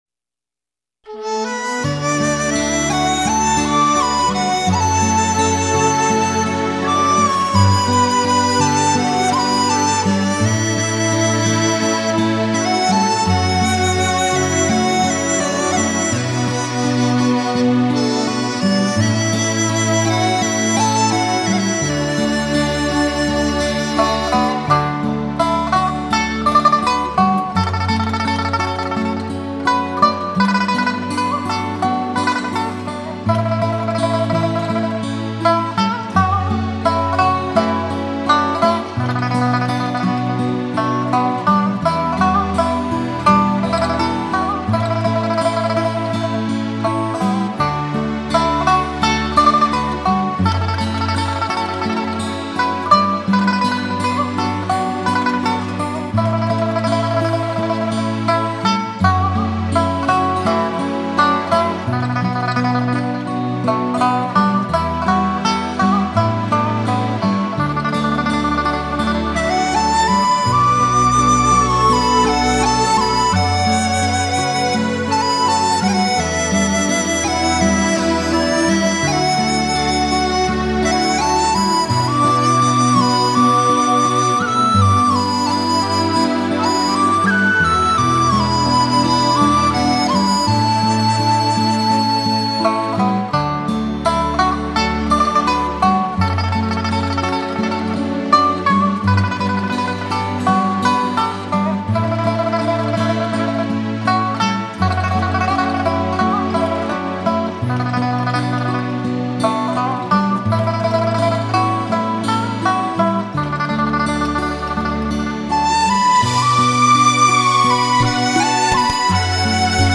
[30/11/2019]长相思...The Lovesickness...（琵琶）